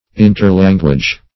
interlanguage - definition of interlanguage - synonyms, pronunciation, spelling from Free Dictionary